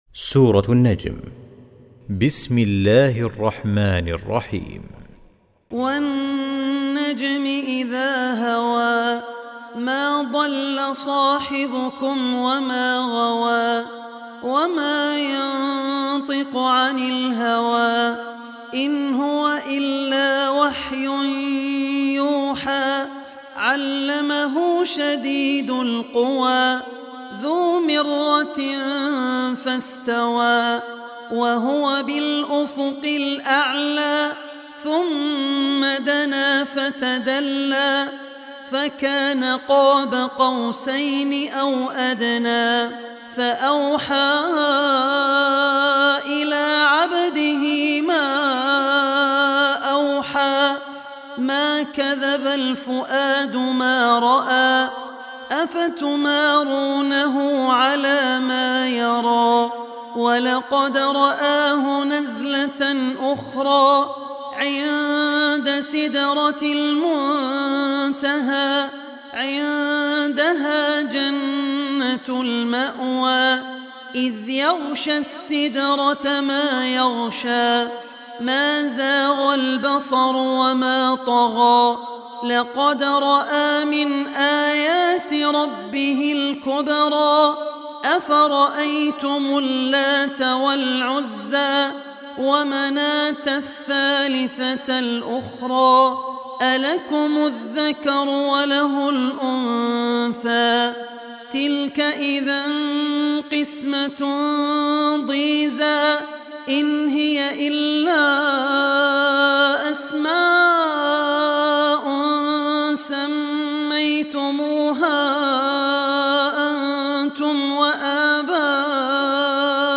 tilawat / recitation